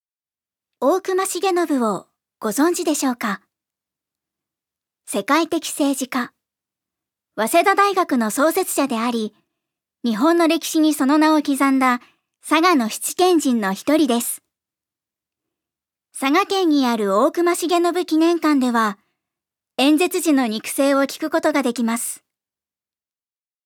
預かり：女性
音声サンプル
ナレーション２